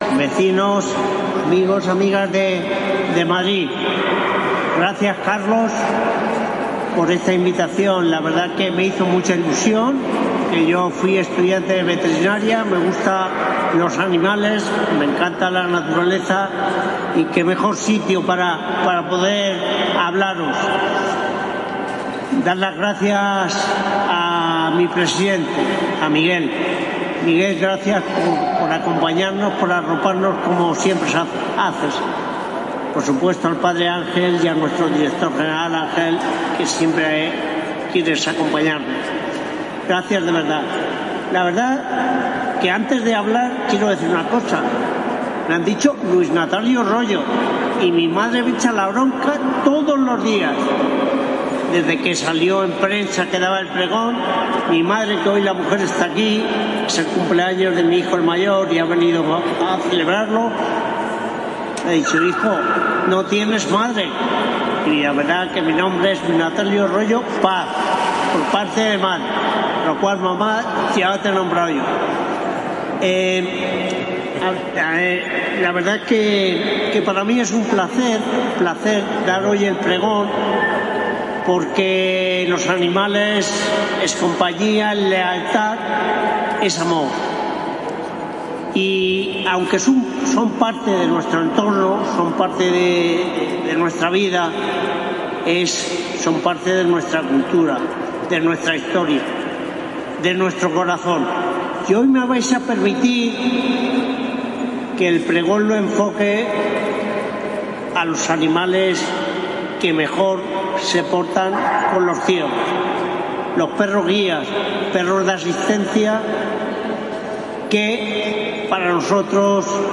Pregón completo